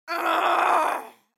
دانلود آهنگ دعوا 26 از افکت صوتی انسان و موجودات زنده
جلوه های صوتی
دانلود صدای دعوا 26 از ساعد نیوز با لینک مستقیم و کیفیت بالا